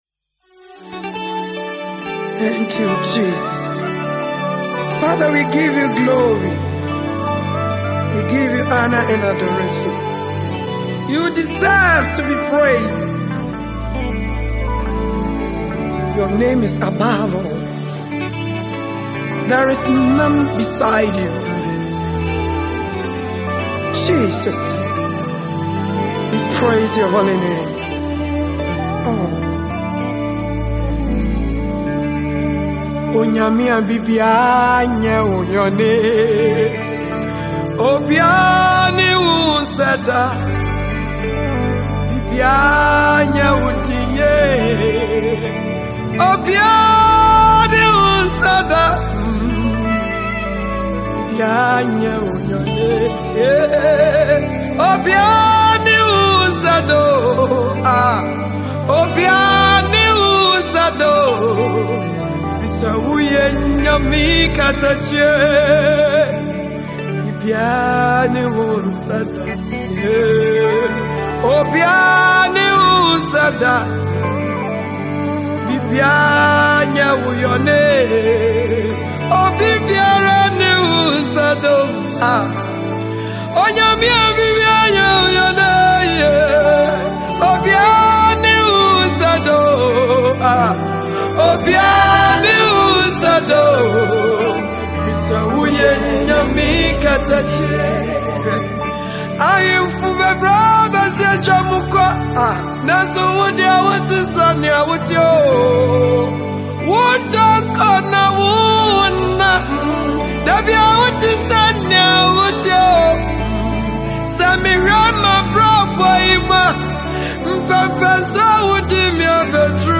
a Ghanaian gospel singer
worship song
Ghana Gospel Music